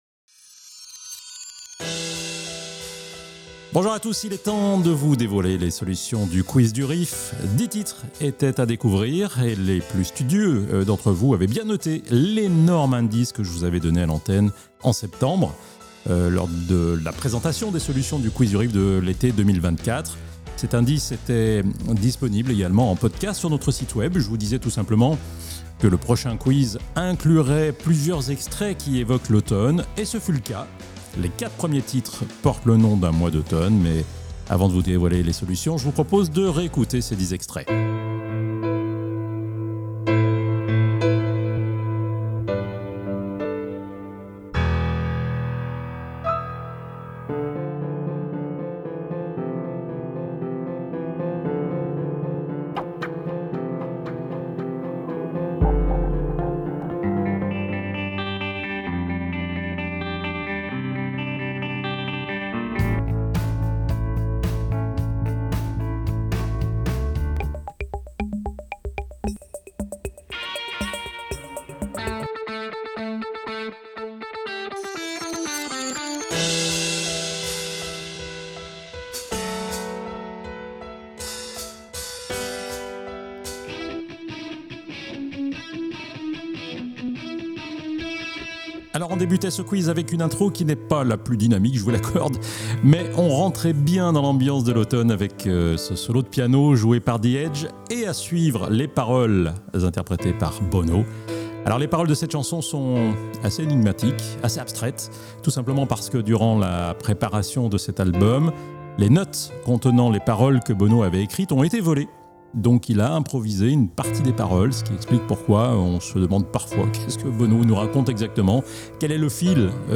Les solutions du Quiz du Riff de l'automne 2024 sont été diffusées à l'antenne le lundi 20 Janvier 2025. Voici l'enregistrement de l'émission.